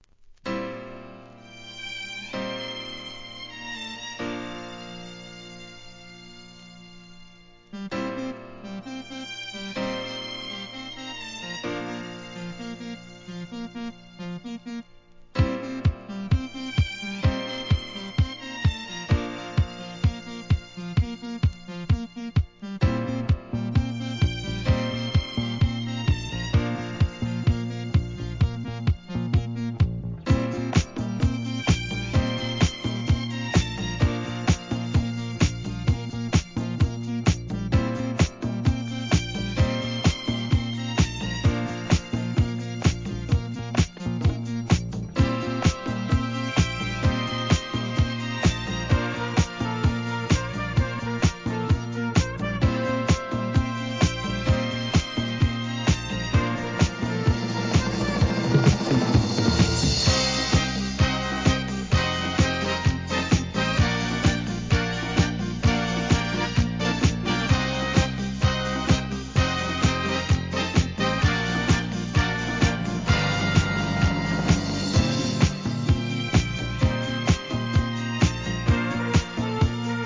¥ 1,100 税込 関連カテゴリ SOUL/FUNK/etc...